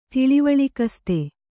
♪ tiḷivaḷikaste